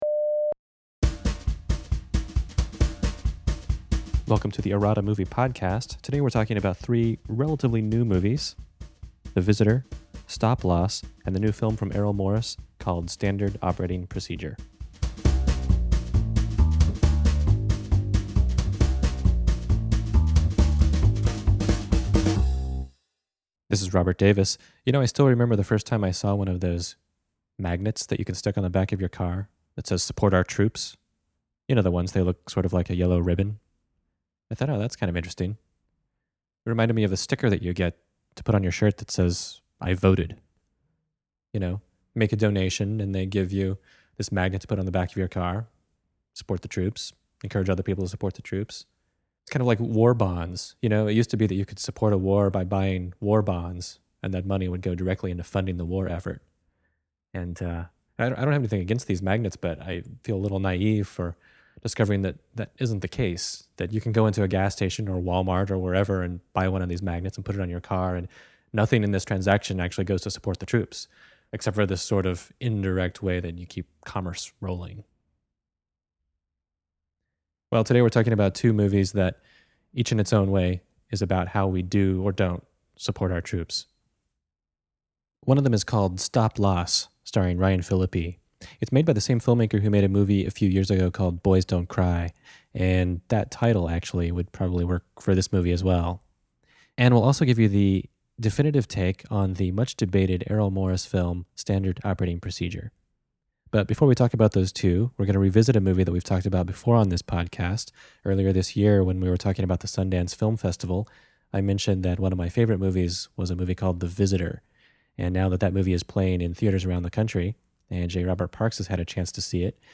0:00 Intro 2:12 The Visitor (McCarthy) 9:31 Film Critic as Consumer Guide 12:37 Interview: Thomas McCarthy 20:59 Stop-Loss (Peirce) 27:32 Standard Operating Procedure (Morris) 45:40 Outro and Photo Caption Contest Winners